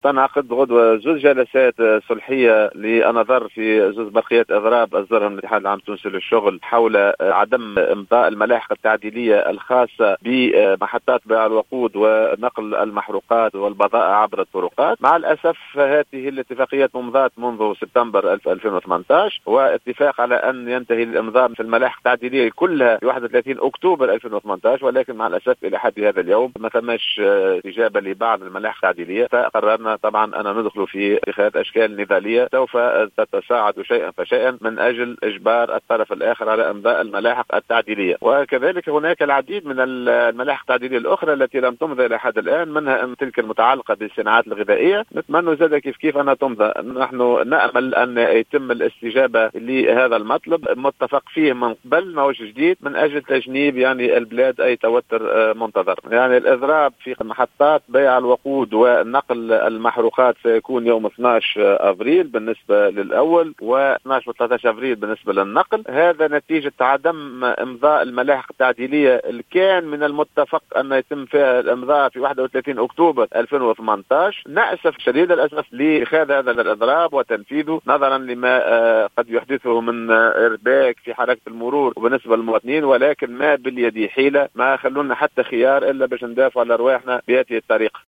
أكد محمد علي بوغدير الأمين العام المساعد لإتحاد الشغل في تصريح للجوهرة اف ام" أن جلستين صلحيتين ستنعقدان يوم غد الأربعاء للنظر في برقيتي اضراب أصدرهما الإتحاد حول عدم امضاء الملاحق التعديلية الخاصة بمحطات بيع الوقود ونقل الوقود والمحروقات عبر الطرقات.